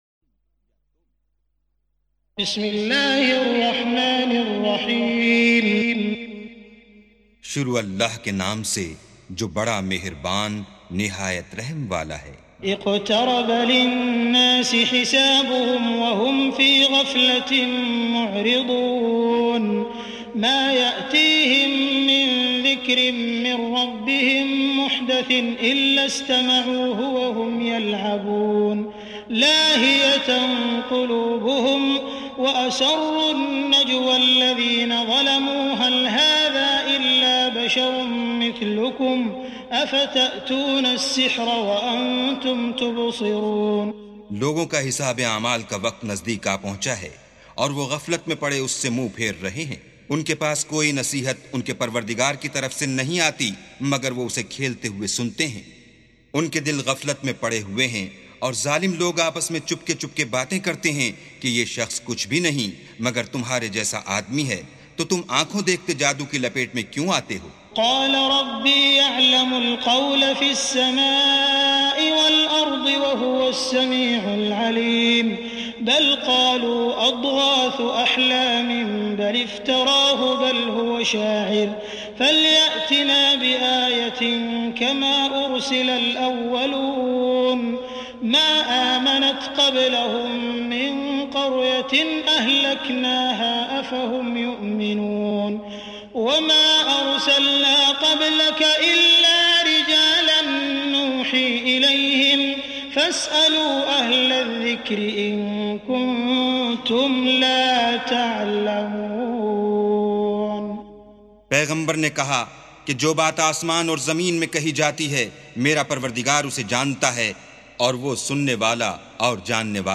سُورَةُ الأَنبِيَاءِ بصوت الشيخ السديس والشريم مترجم إلى الاردو